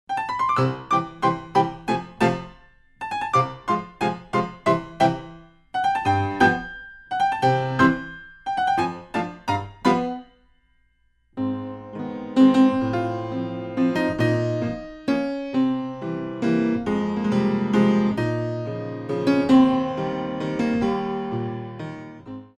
Presto - Moderato - Adagio - Allegro